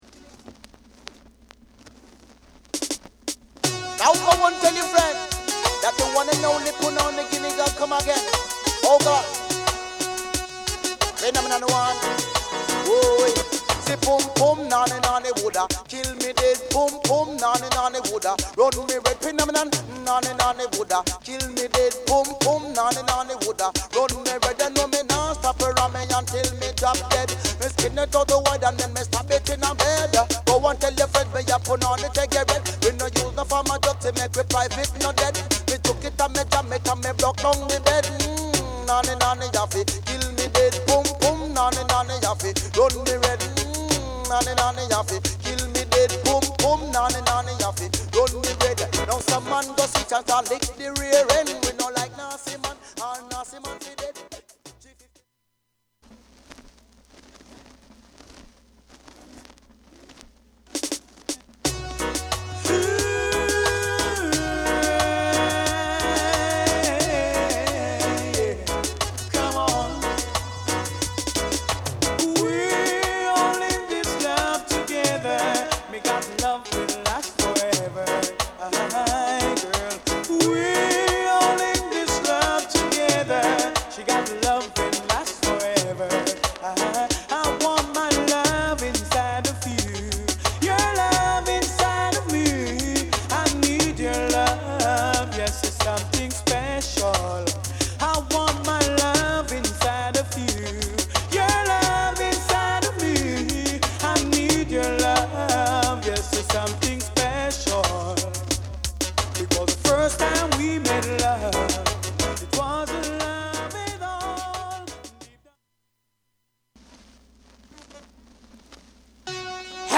REGGAE / DANCEHALL